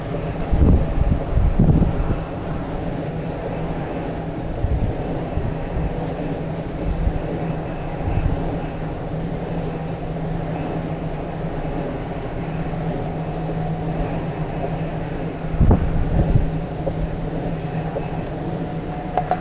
Abb. 05: Geräusche eines Windgenerators mit Getriebe, ENRON Wind 1,5,
Die periodisch auftretenden helleren senkrechten Bereiche im Diagramm gehören zu den Momenten, wenn der Flügel gerade am Mast vorbeistreicht - Flügelschlag.
Etwa 5 Ereignisse in 5 Sekunden.